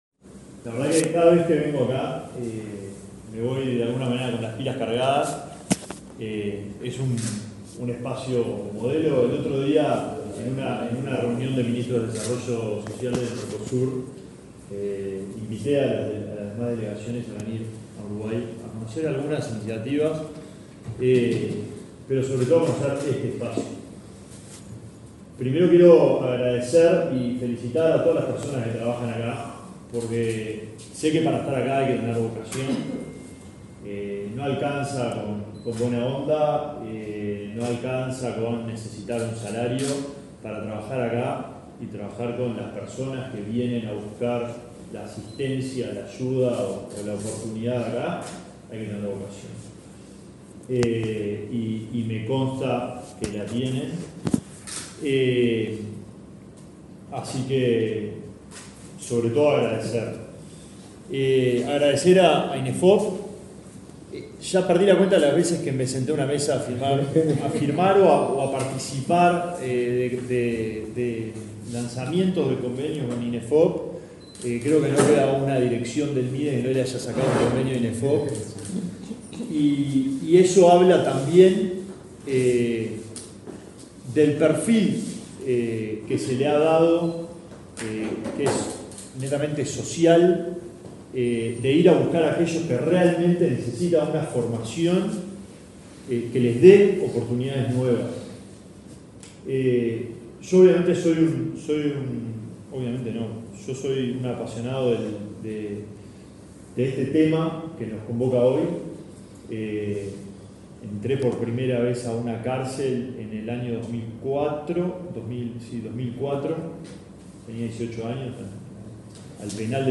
Declaraciones del ministro de Desarrollo Social, Alejandro Sciarra